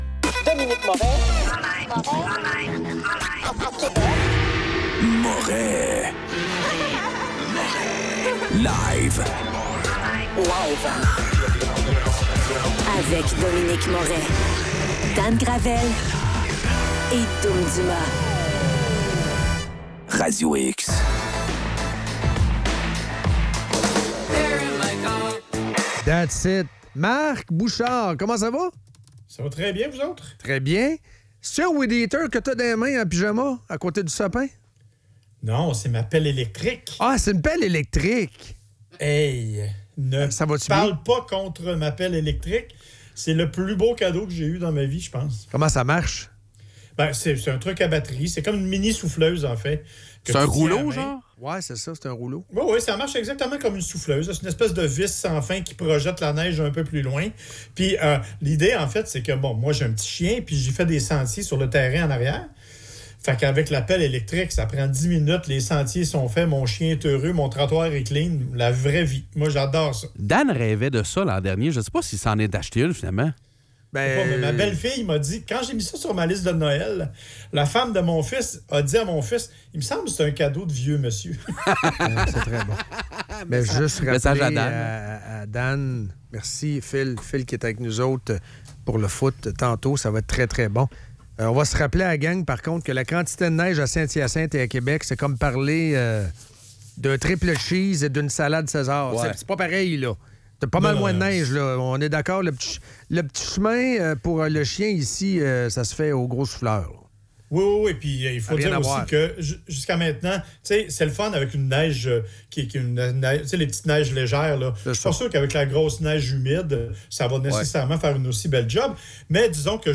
En chronique